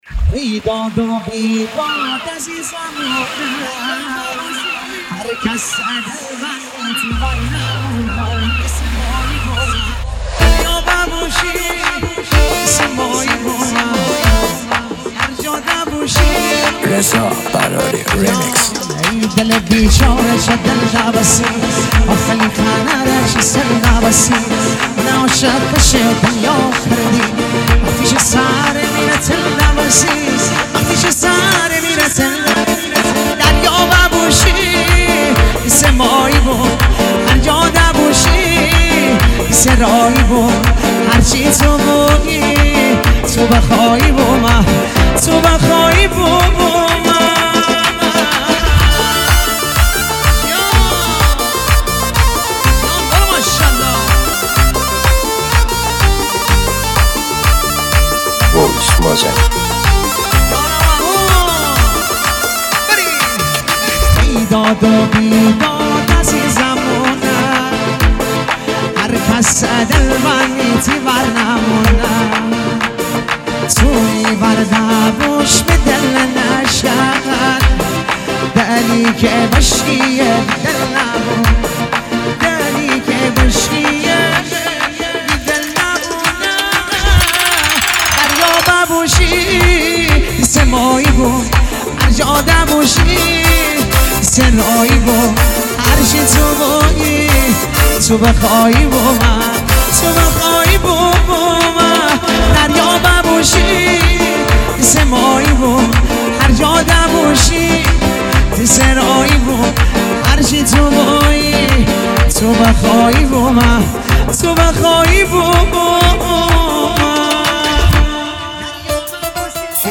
جشنی
آهنگی در سبک آهنگ های جشنی مازندرانی